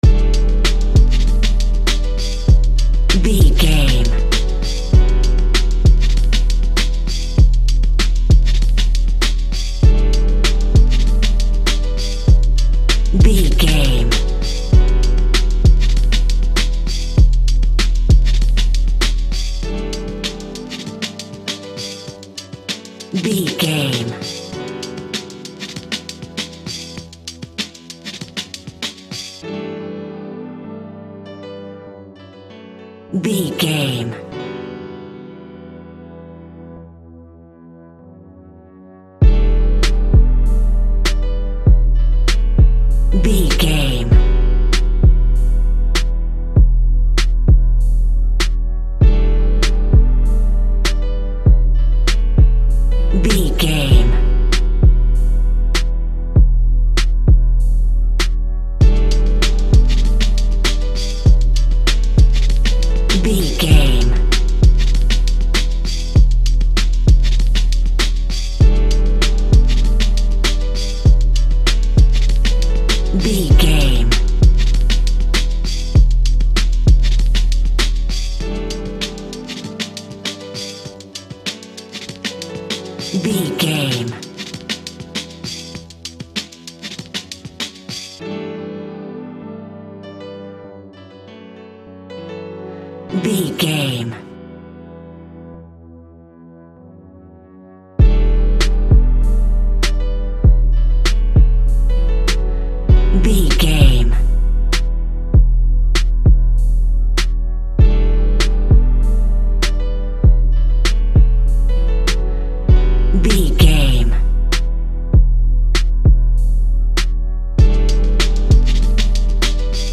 Ionian/Major
drums
relaxed
smooth
mellow